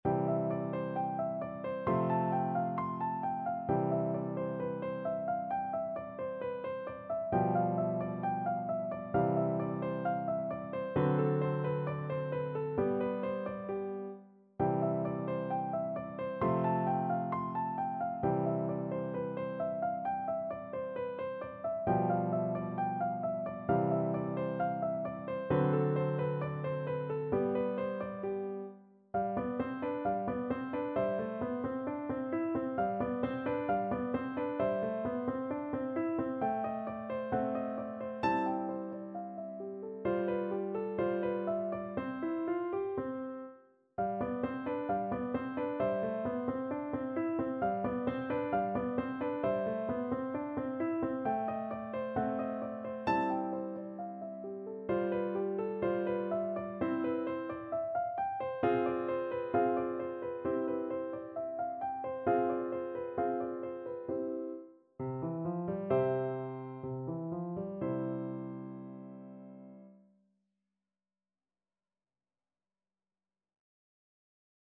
No parts available for this pieces as it is for solo piano.
4/4 (View more 4/4 Music)
Allegro moderato (=132) (View more music marked Allegro)
Piano  (View more Easy Piano Music)
Classical (View more Classical Piano Music)